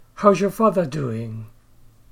/z/ (followed by /j/) becomes /ʒ/